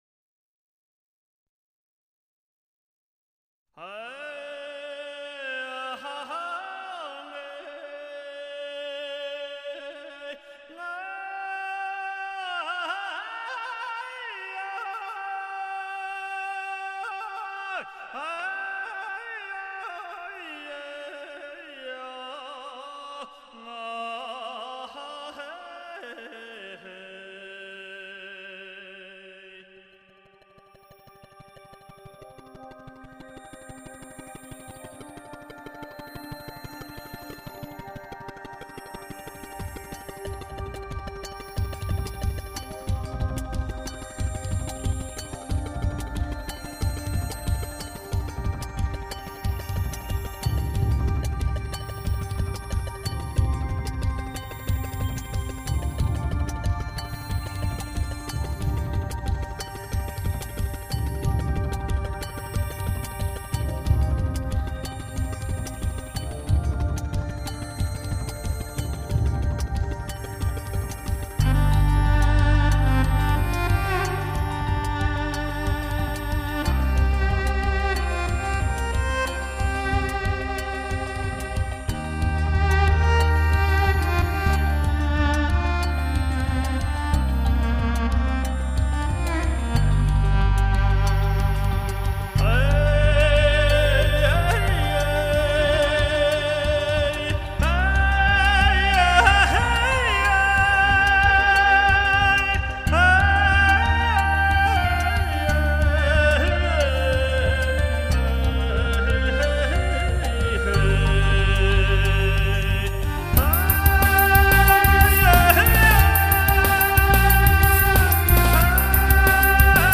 键盘 Keyboard/电子吹奏合成器
小提琴 Violin
大提琴 Cello
竹笛 Bamboo Flute
电子乐混合来自蒙古大草原的音乐元素：吟唱、长调、马头
琴……融汇电子、西洋、民族、古典、现代等多样曲风，精彩原创，唯美动听，采撷中华传统文化元素，